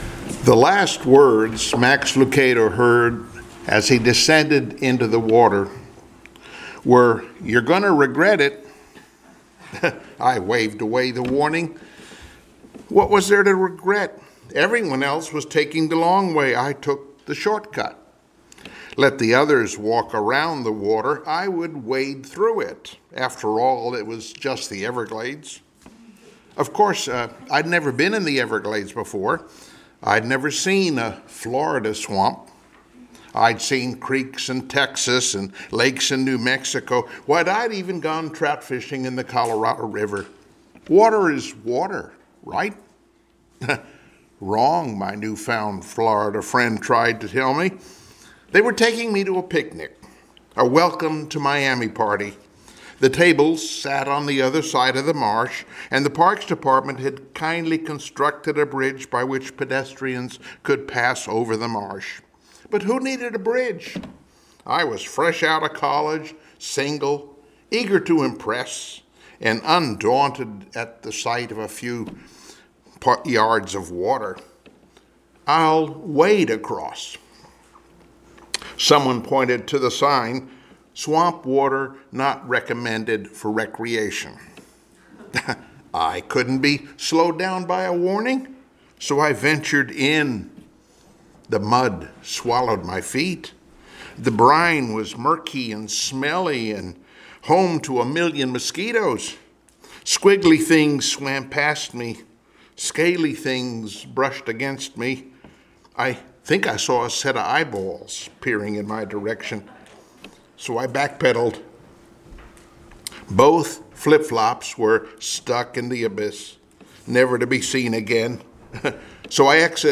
Joshua Passage: Joshua 8:30-35 Service Type: Sunday Morning Worship Topics